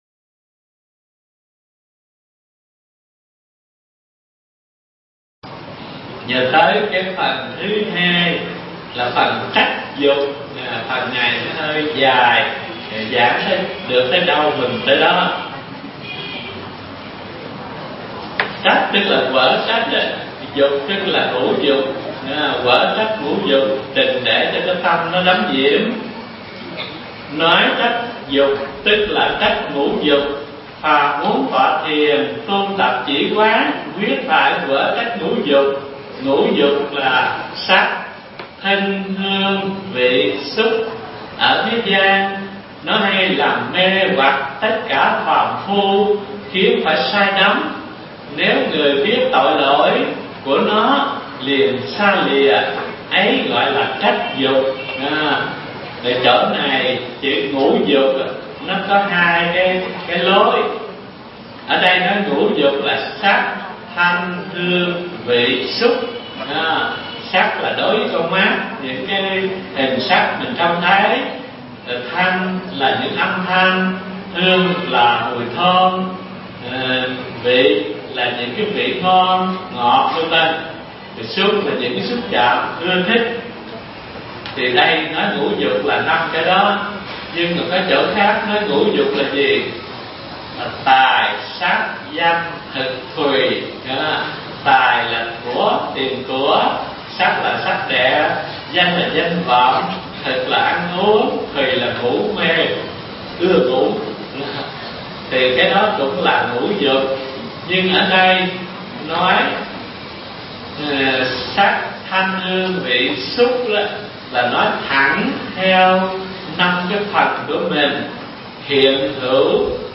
Mp3 Thuyết Pháp Thiền Căn Bản 5 – Phần Chánh Tông – Trách Dục – Hòa Thượng Thích Thanh Từ giảng tại chùa Ấn Quang từ ngày 24 tháng 10 năm 1998 đến ngày 24 tháng 2 năm 2001